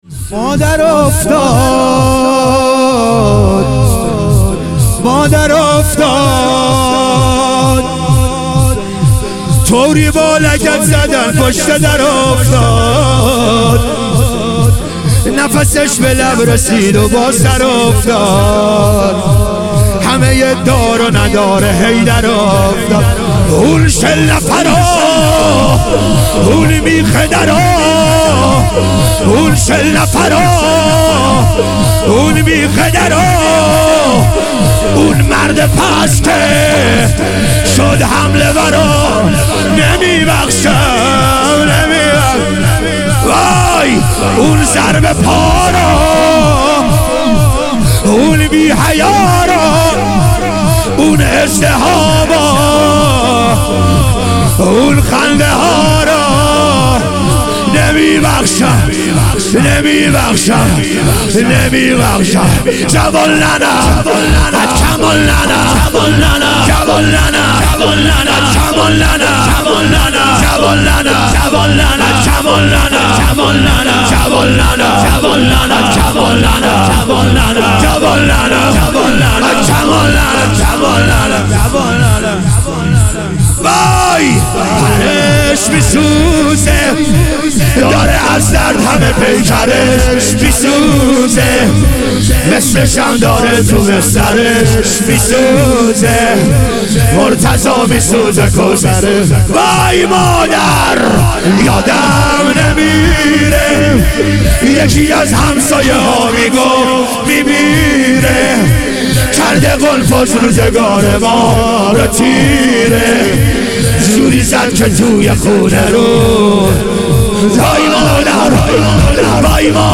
شام غریبان حضرت زهرا علیها سلام - شور